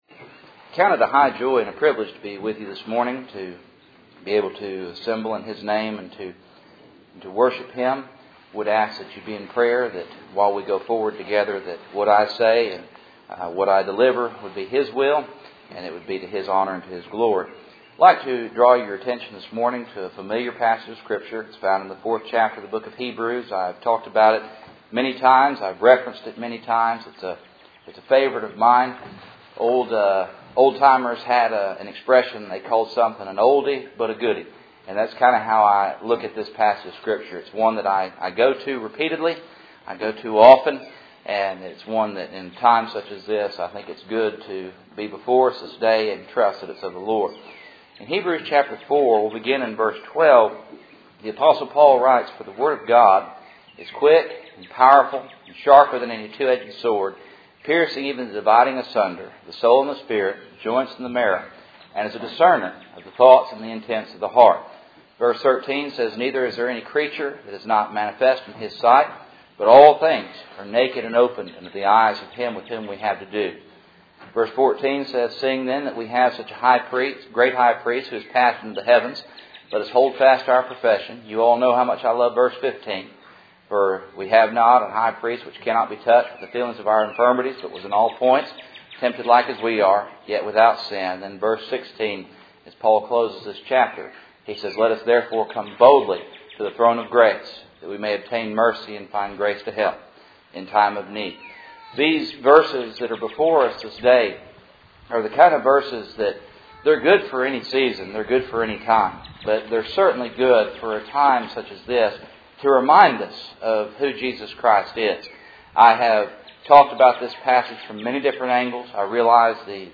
Cool Springs PBC Sunday Morning